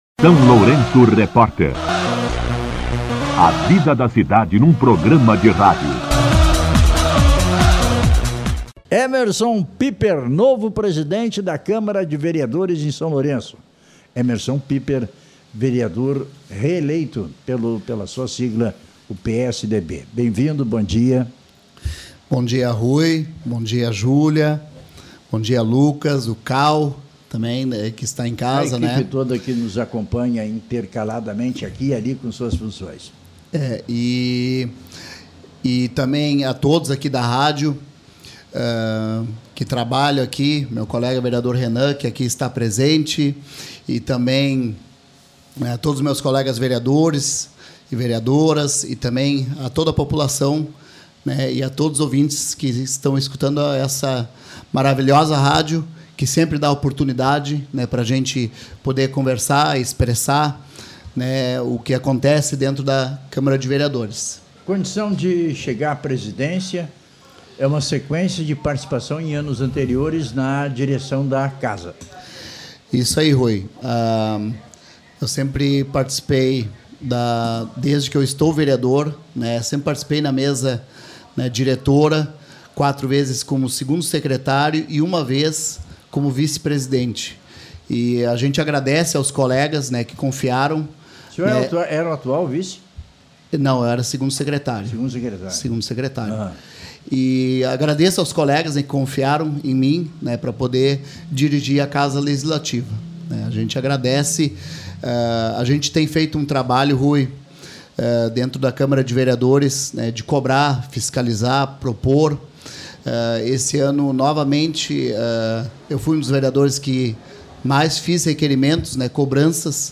Entrevista com o presidente, vereador Emerson Kabke Pieper, e o 1º secretário, vereador Renan Hartwig.
O SLR Rádio recebeu, na manhã desta quarta-feira (24), o presidente e o 1º secretário da nova Mesa Diretora da Câmara Municipal de São Lourenço do Sul, eleita na última segunda-feira, 22 de dezembro, durante Sessão Ordinária. Participaram da entrevista o presidente, vereador Emerson Kabke Pieper, e o 1º secretário, vereador Renan Hartwig.
Entrevista-Emerson-Piepper.mp3